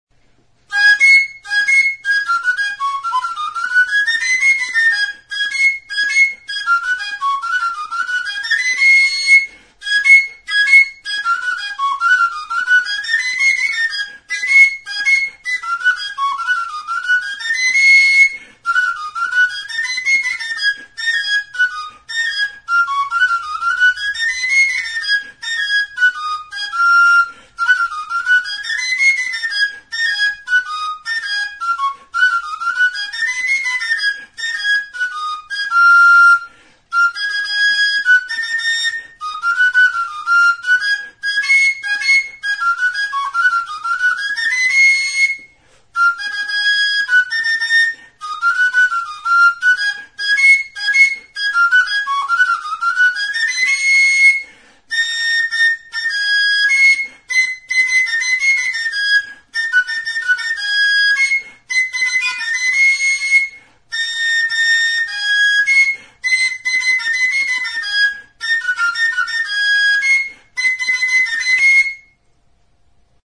Aerófonos -> Flautas -> Recta (de una mano) + flautillas
Grabado con este instrumento.
TXISTUA
Hiru zuloko flauta zuzena da.
Fa# tonuan afinaturik dago.